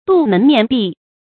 杜门面壁 dù mén miàn bì
杜门面壁发音